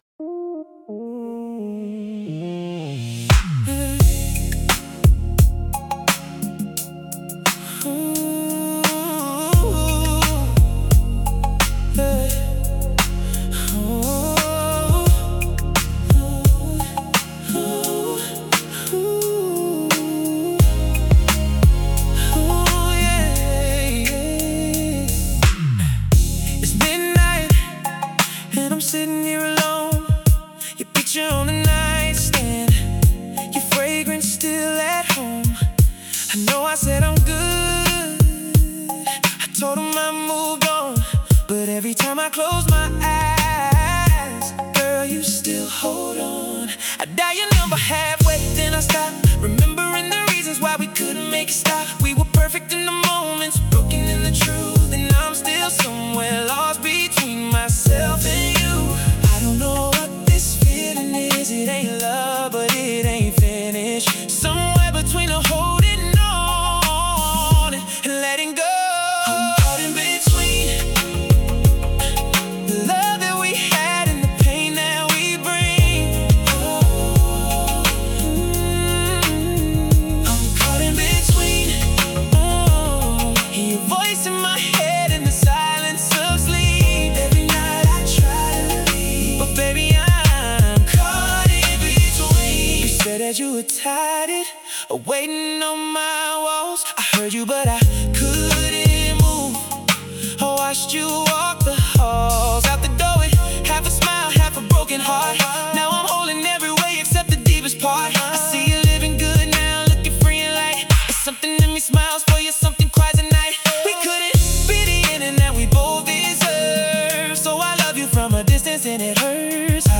Sensual | Melancholic 85 BPM